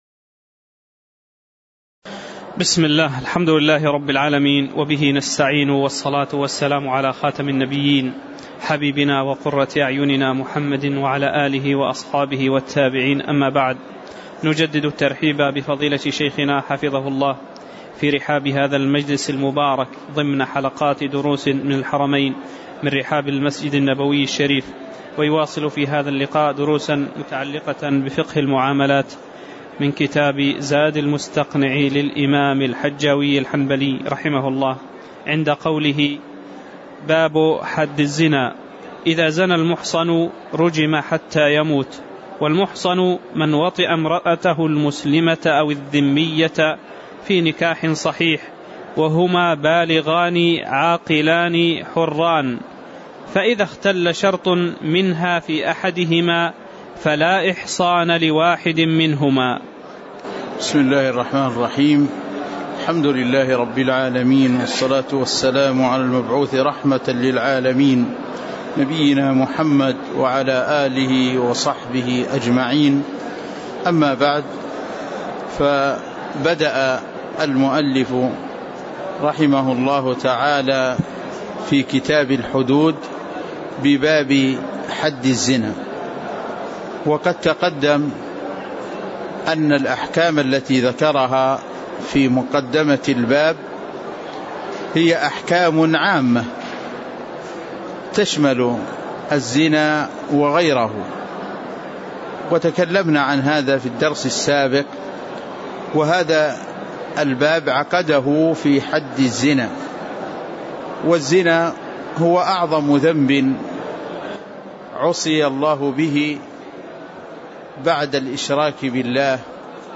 تاريخ النشر ١٥ جمادى الأولى ١٤٣٨ هـ المكان: المسجد النبوي الشيخ